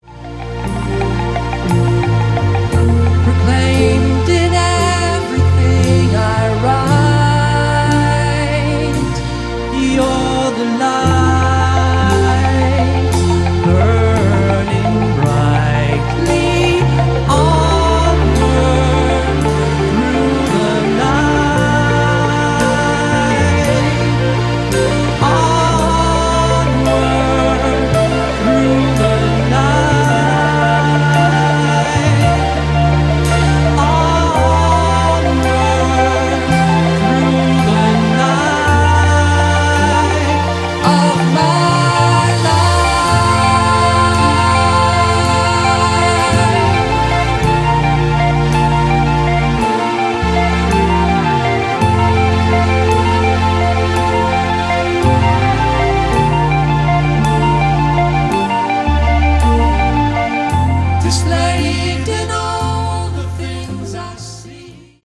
Category: Prog Rock